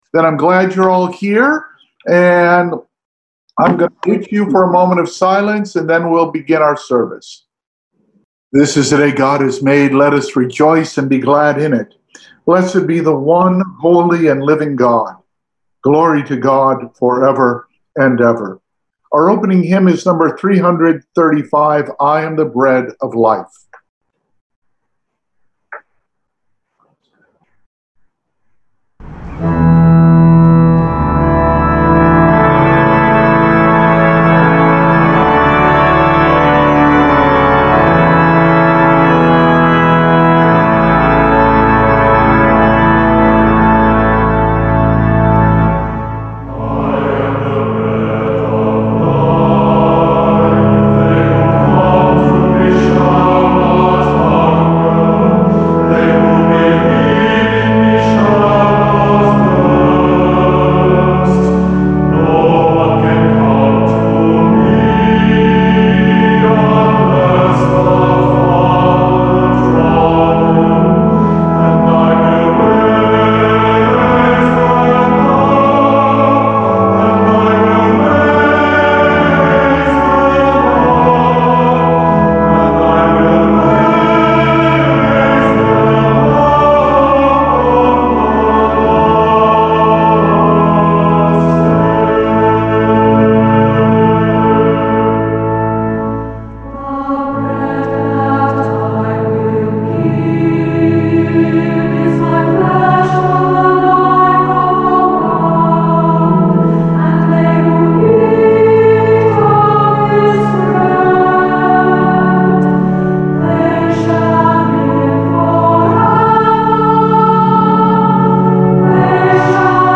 Audio only of service.